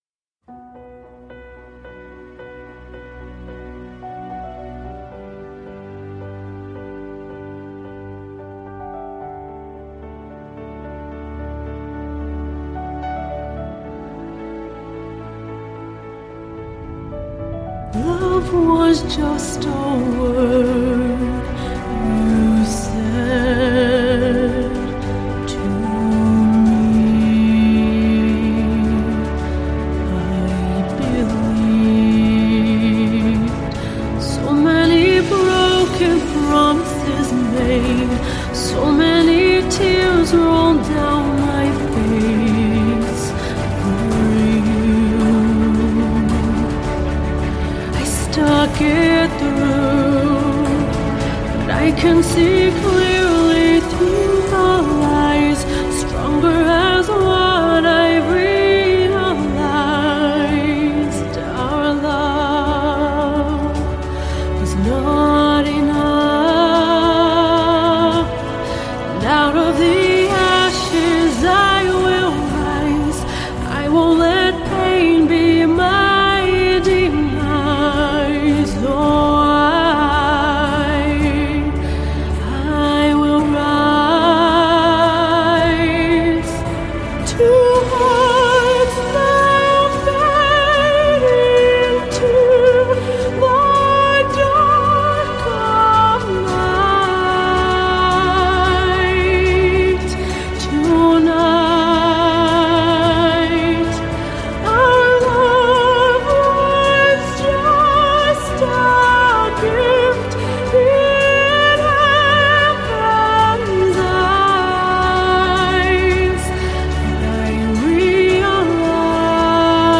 a Canadian soprano from Toronto